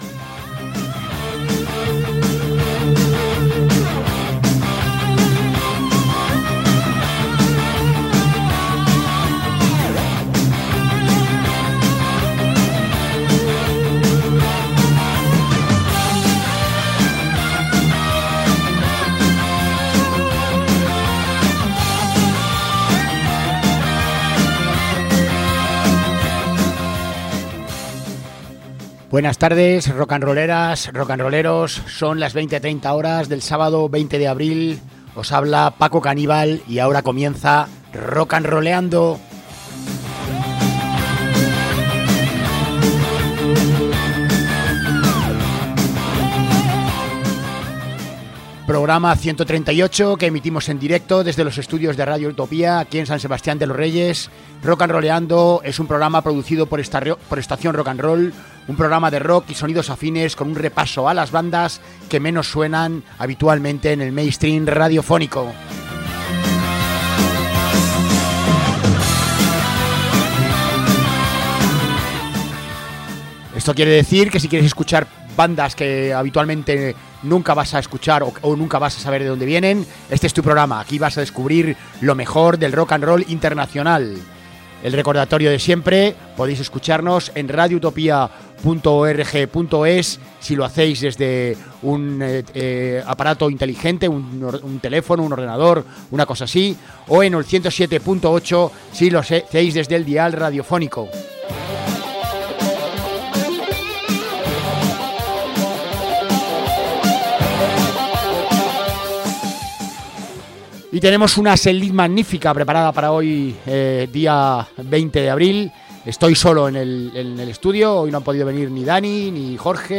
Centrada en un denso toque de guitarra de blues, también presenta los ganchos pop característicos de la banda y grandes armonías.
La banda sigue por los sonidos que le están funcionando también, riffs eléctricos, melodías pegadizas que cabalgan perfectamente en el blues rock.